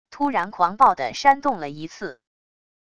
突然狂暴的扇动了一次wav音频